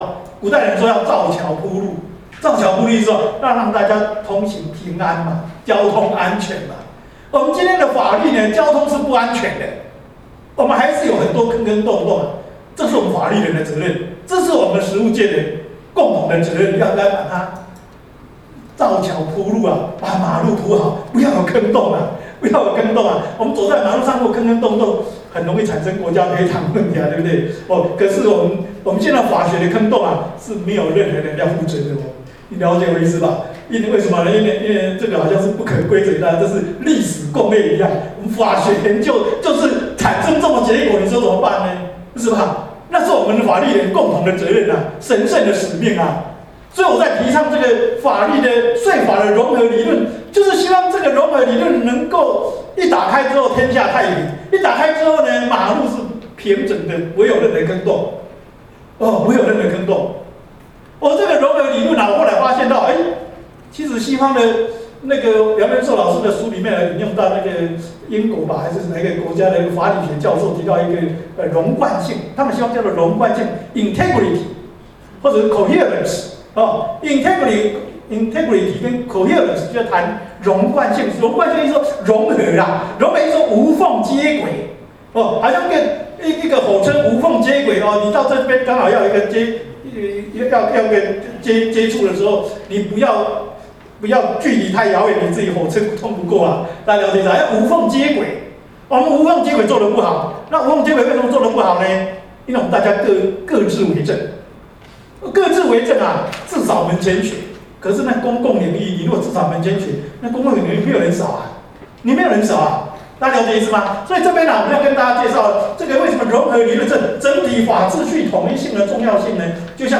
法學方法論-最高檢察署法學論壇講座-最高檢察署